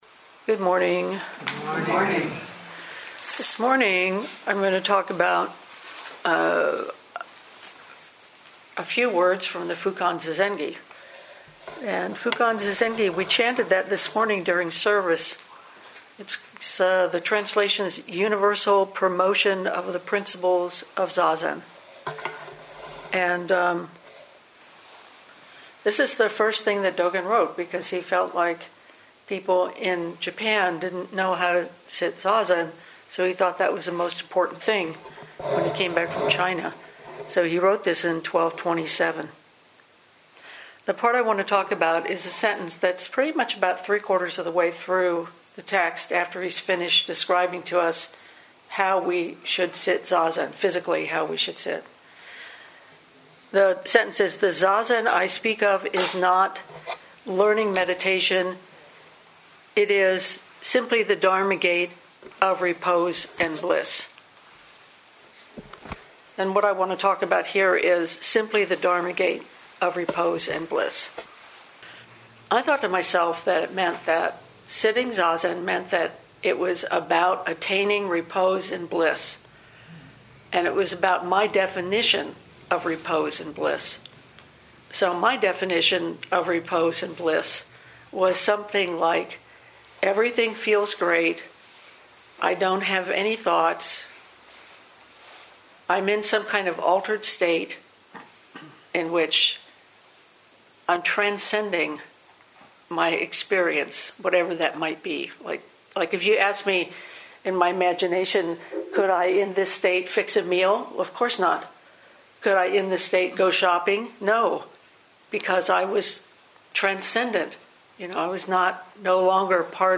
2020 in Dharma Talks